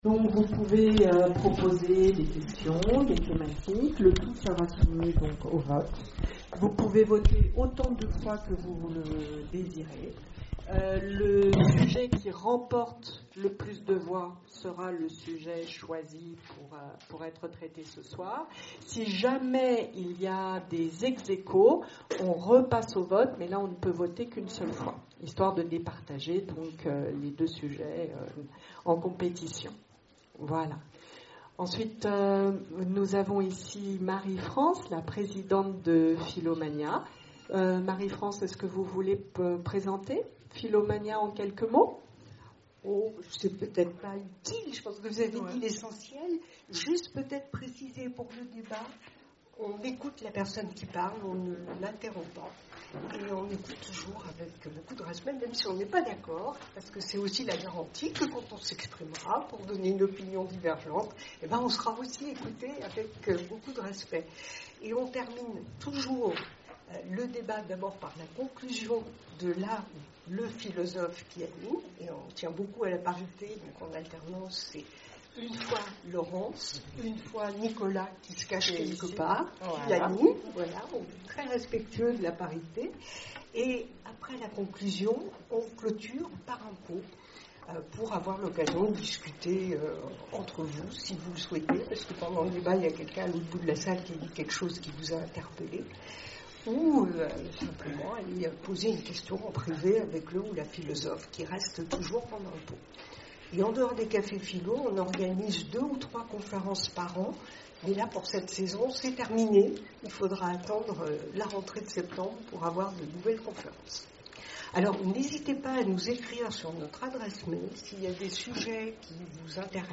Conférences et cafés-philo, Orléans
CAFÉ-PHILO PHILOMANIA Est-ce-que l’oubli est nécessaire à la vie humaine ?